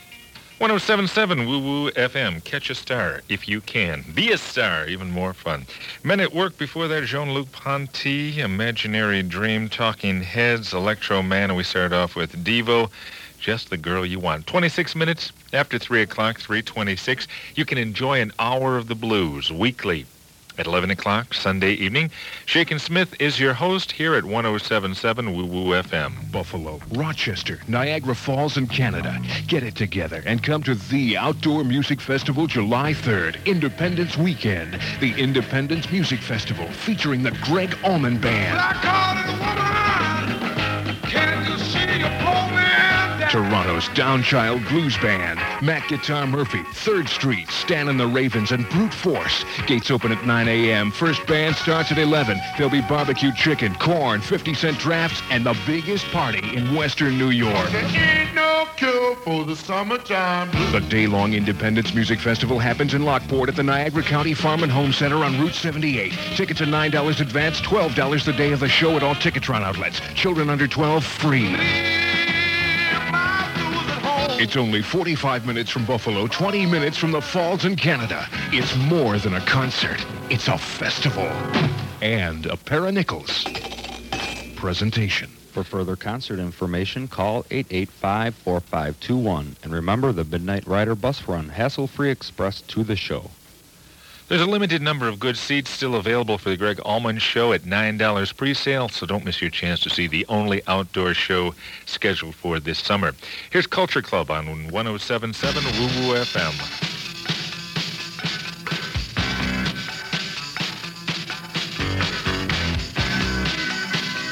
Cool Airchecks and More: